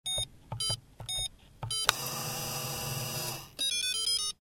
Звуки сейфа
Звук клавиш при наборе кода на сейфе